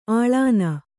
♪ āḷāna